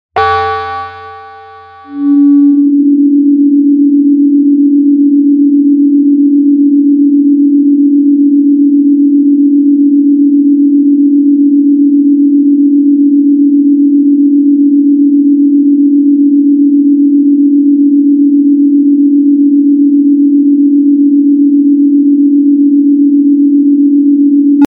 🔍This forbidden solfeggio frequency (285Hz) is like Ctrl+Z for your cells